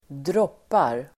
Uttal: [²dr'åp:ar]